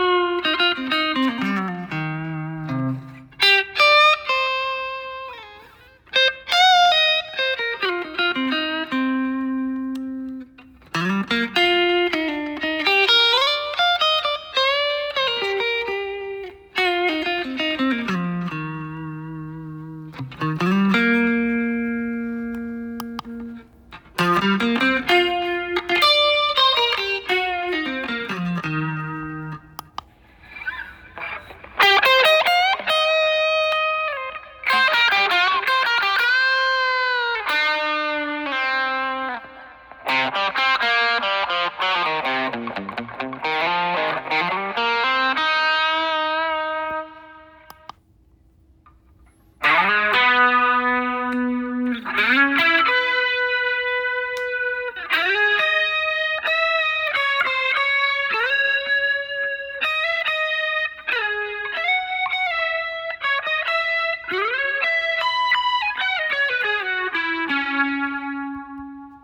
ギターで弾いた音をワイヤレスでヘッドホンに飛ばしてそのヘッドホンの中にはアンプとエフェクター何十種類も入っている 今それで録音した音を聞いてみてね❣
Now, the sound you play on your guitar can be transmitted wirelessly to your headphones, and inside those headphones are dozens of built-in amps and effects. Take a listen to the recording I made with that setup!